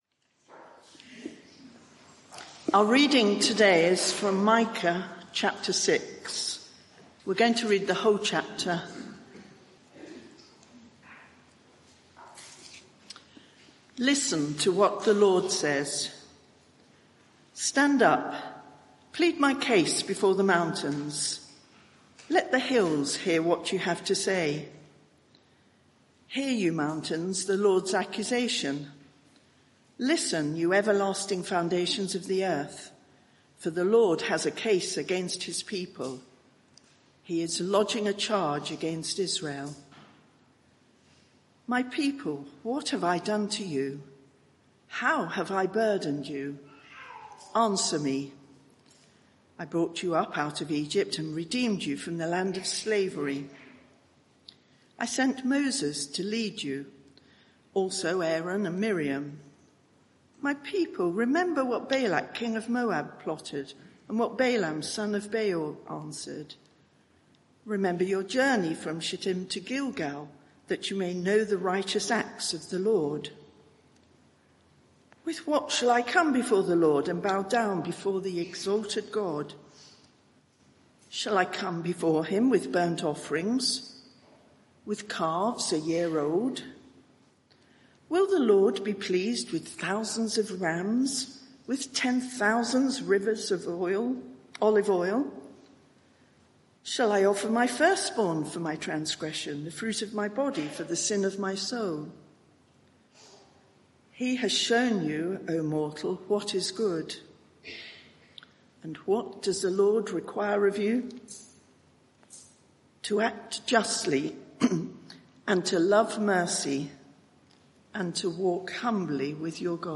Media for 11am Service on Sun 29th Jun 2025 11:00 Speaker
Micah 6:1-16 Series: Who is like the Lord our God? Theme: Injustice Condemned There is private media available for this event, please log in. Sermon (audio) Search the media library There are recordings here going back several years.